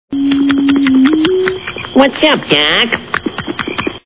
Looney Toons TV Show Sound Bites